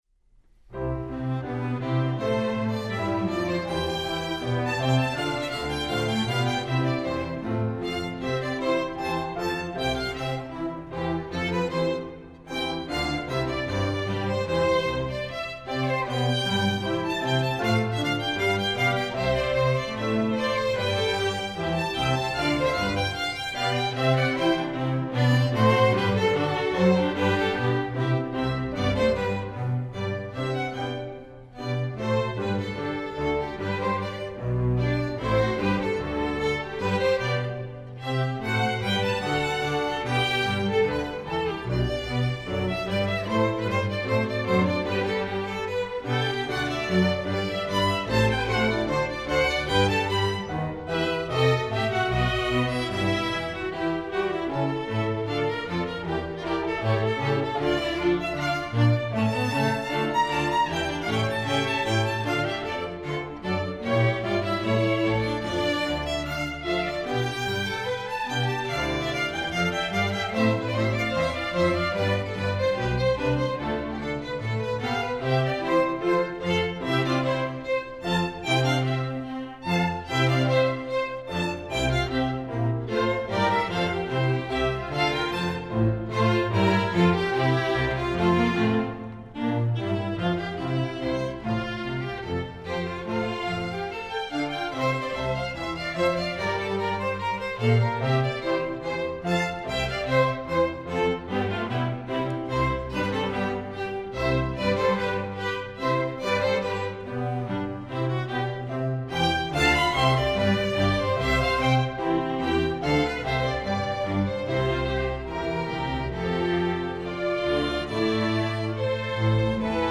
Instrumentation: string orchestra
classical